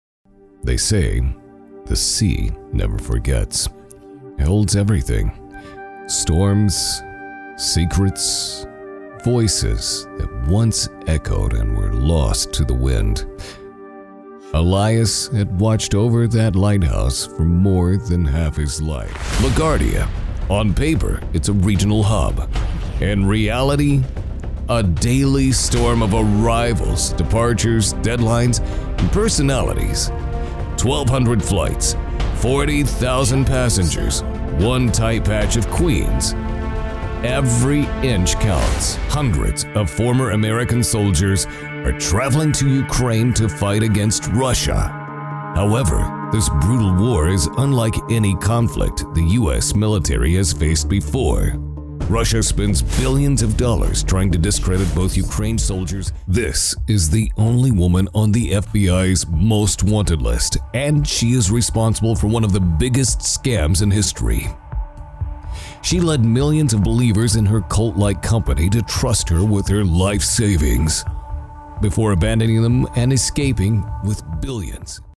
Since 2001, I’ve worked nationally and internationally as a voice over talent, delivering broadcast-ready reads for TV, radio, automotive, and documentary projects from a professional studio.
Documentary
Middle Aged